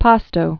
(pästō)